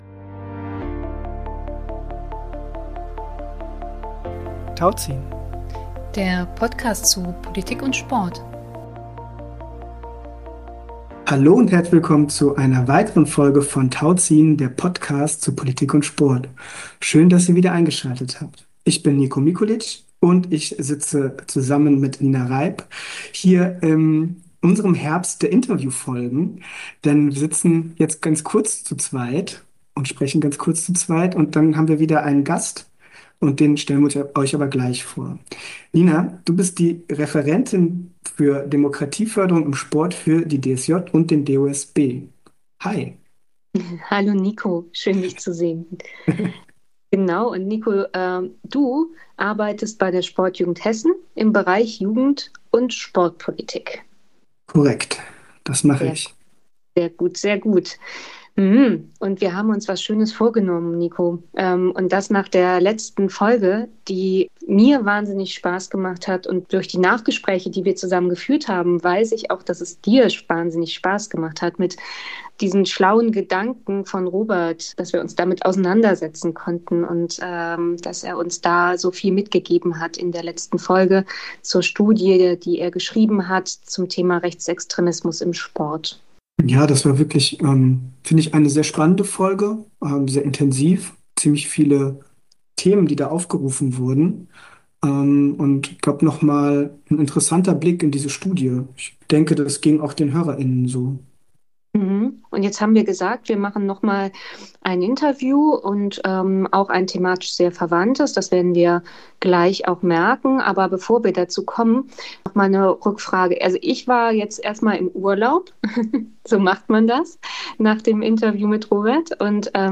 Sport und die umkämpften Werte - Gespräch